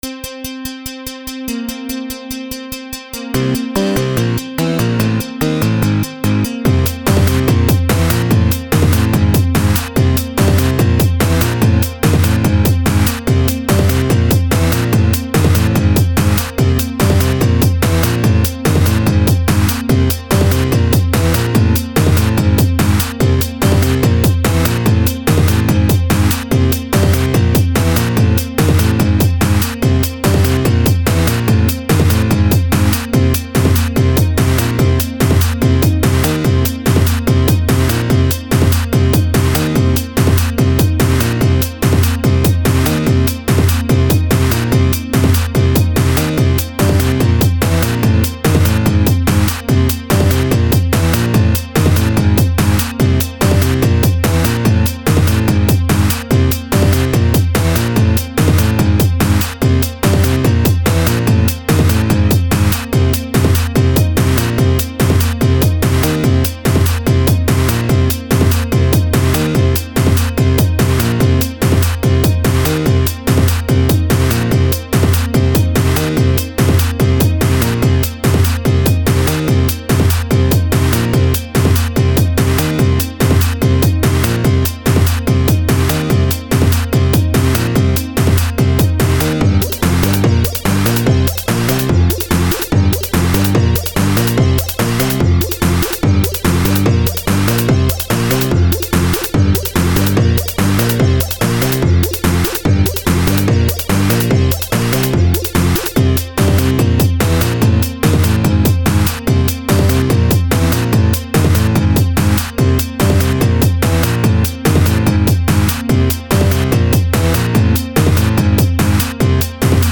Upbeat dance track.
• Music is loop-able, but also has an ending